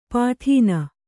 ♪ pāṭhīna